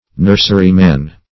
Nurseryman \Nurs"er*y*man\, n.; pl. Nurserymen.